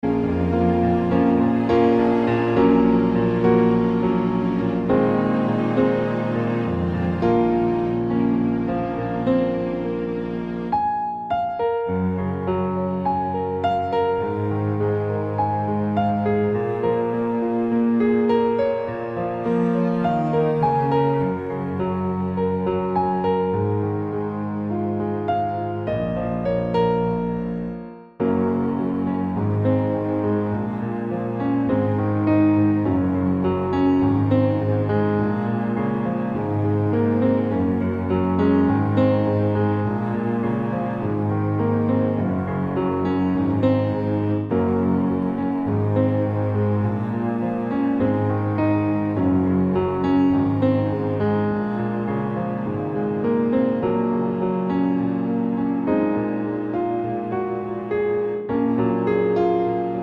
Down 2 Semitones